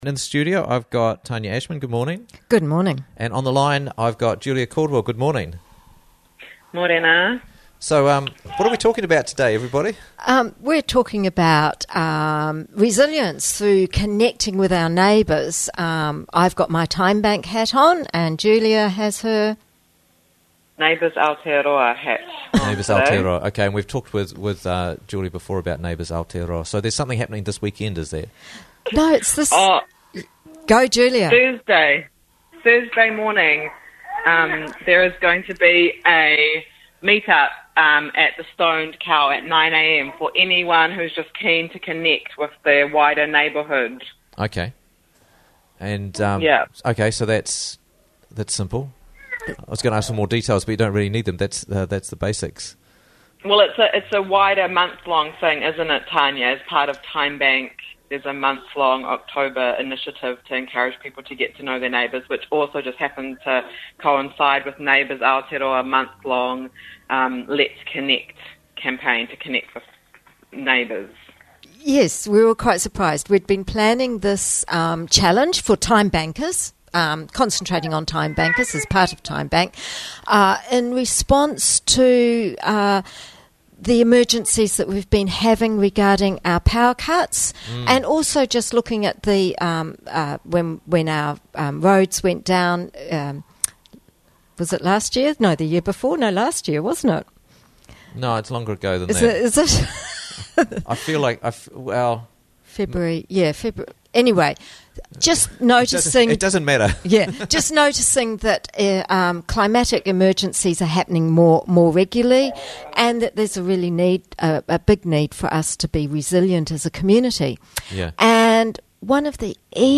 Neighbourhood Gatherings - Interviews from the Raglan Morning Show